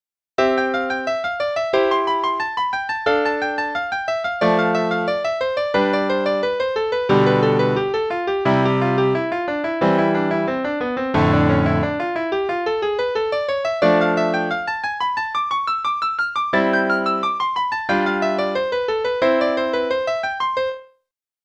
Czerny 14 (qn=90).mp3